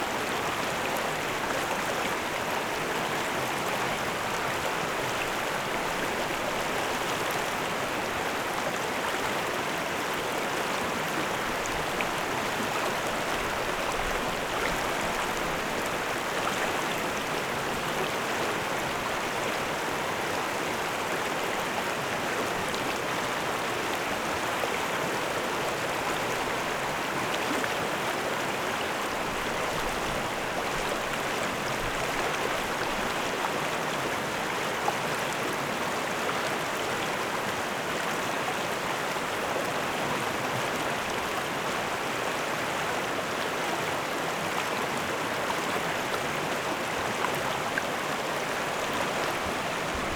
River.wav